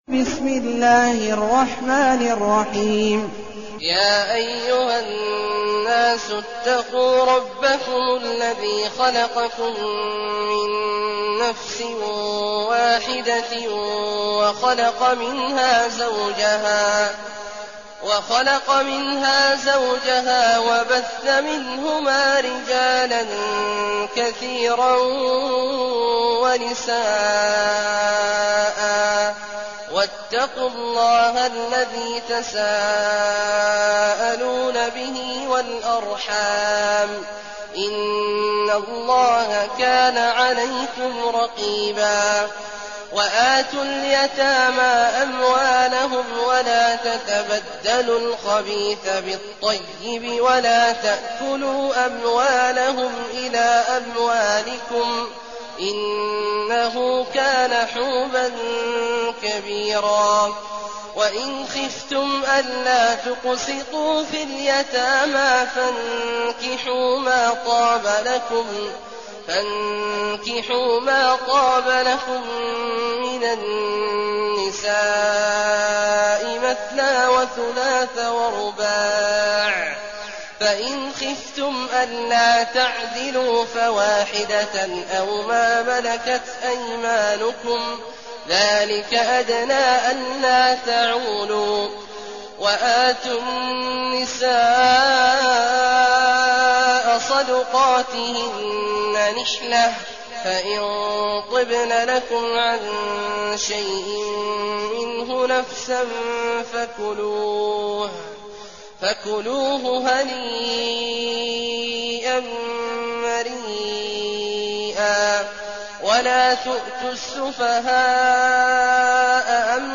المكان: المسجد النبوي الشيخ: فضيلة الشيخ عبدالله الجهني فضيلة الشيخ عبدالله الجهني النساء